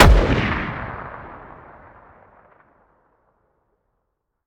weap_br1_fire_plr_atmo_ext1_05.ogg